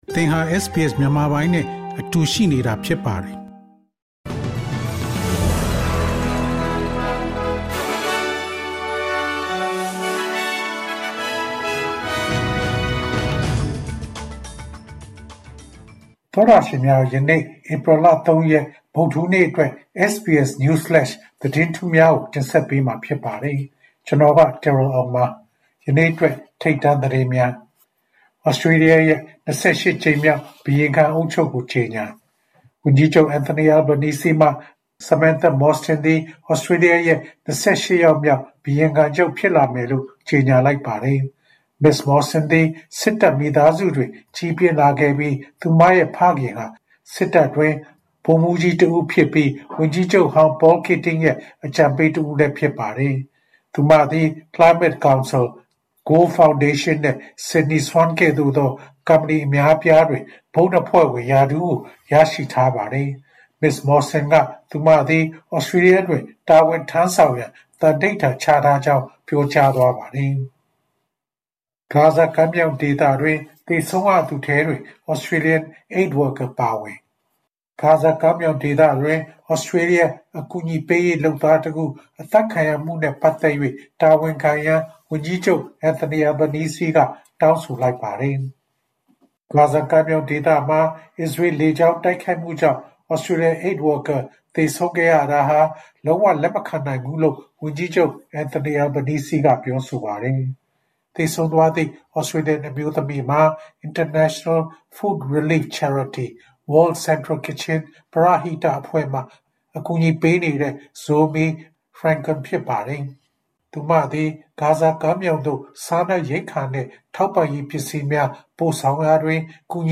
ဧပြီလ ၃ ရက် တနင်္လာနေ့ SBS Burmese News Flash သတင်းများ။